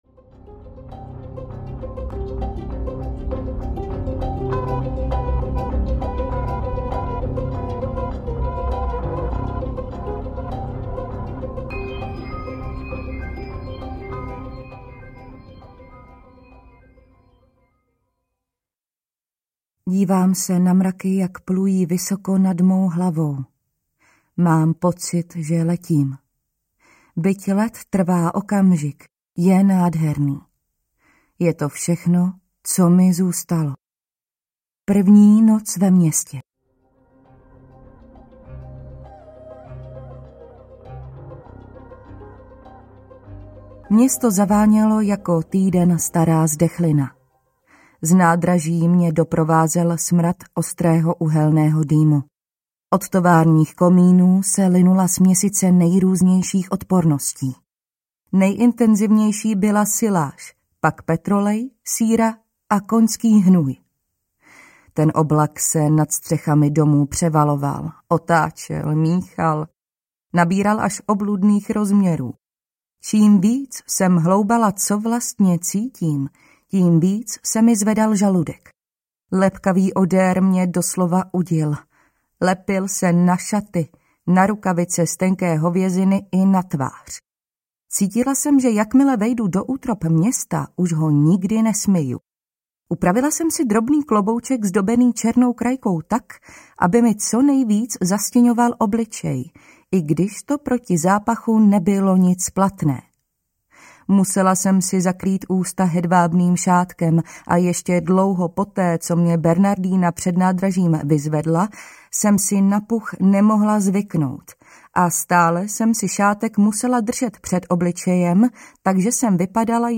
Pomiluj smrt audiokniha
Ukázka z knihy